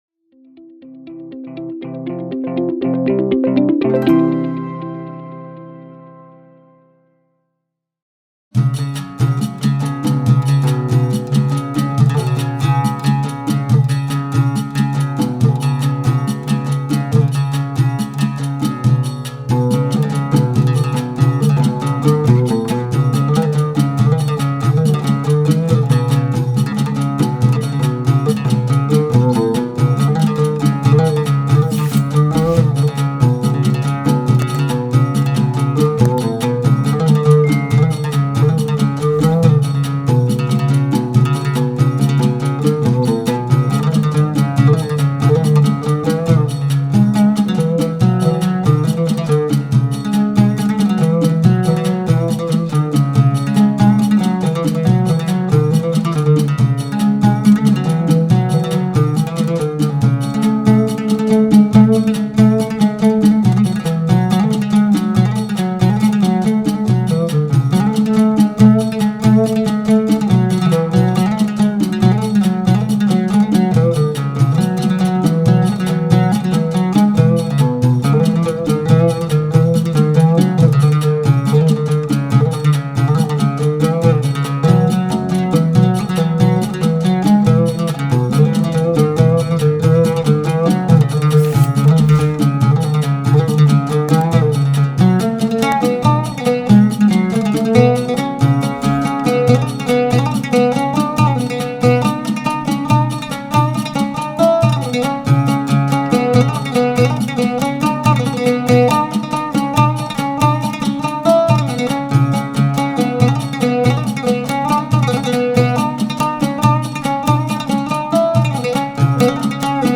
صوته الدافئ الفريد من نوعه
الأغنية الرومانسية